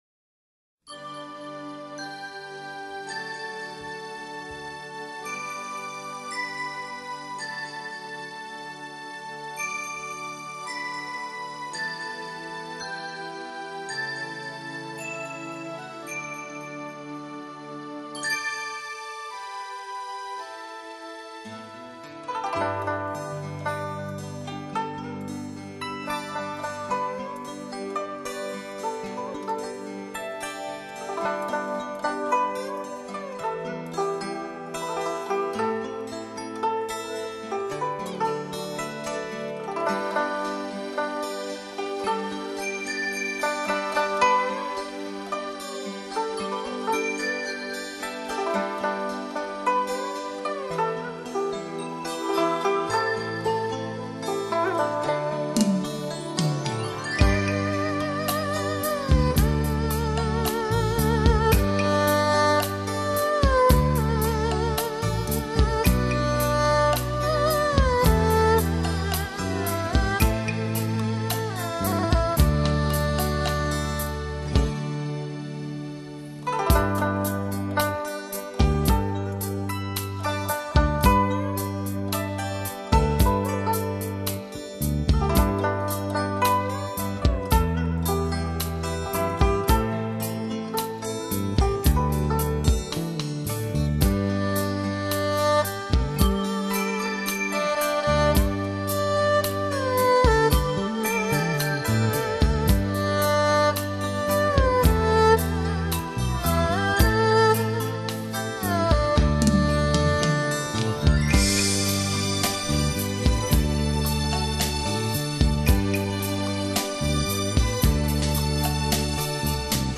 古筝演奏）(低品质)